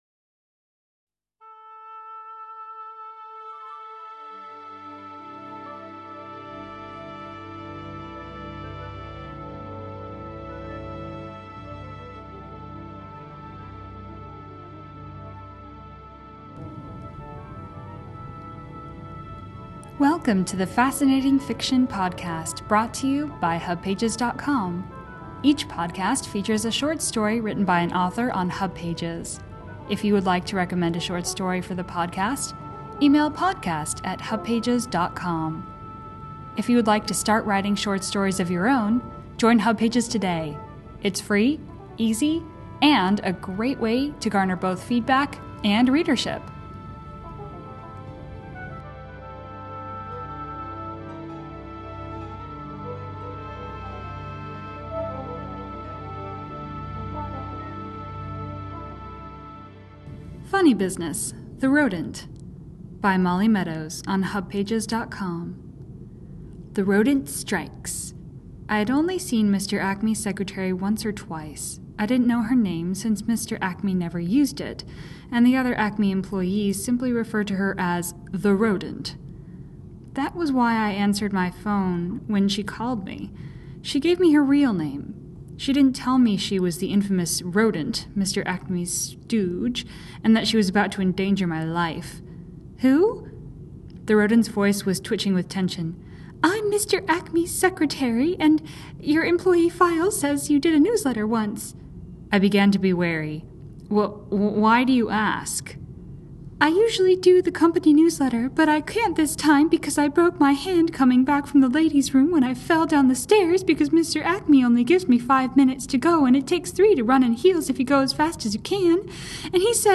recorded version of the story